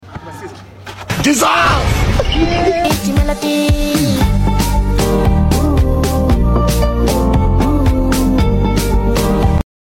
Laugh softly